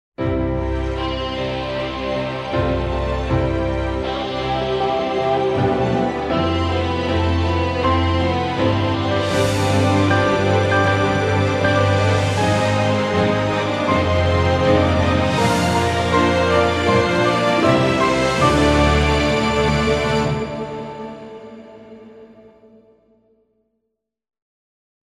epique - guitare - violons - paysage - aerien